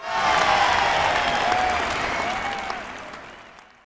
post_match_draw_cheer_02.wav